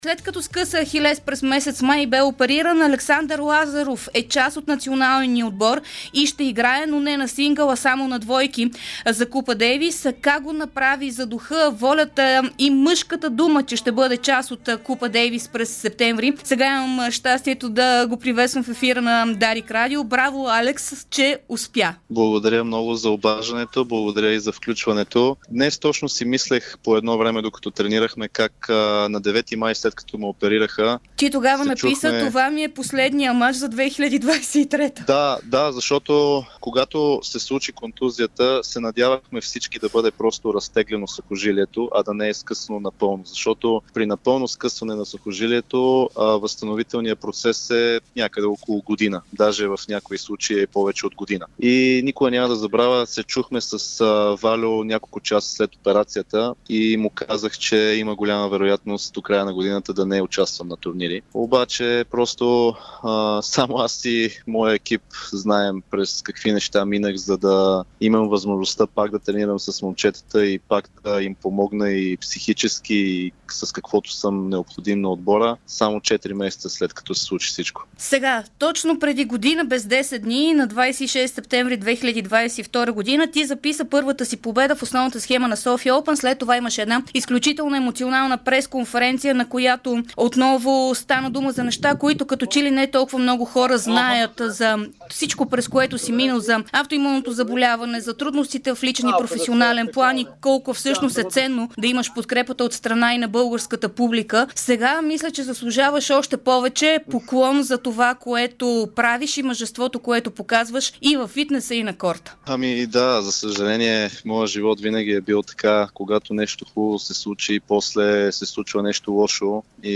специално интервю в Спортното шоу на Дарик радио преди началото на Купа Дейвис.